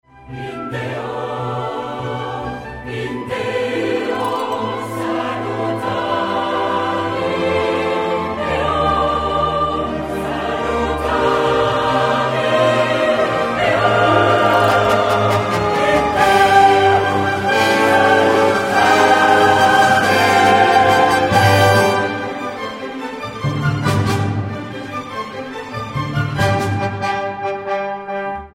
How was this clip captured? Extraits du concert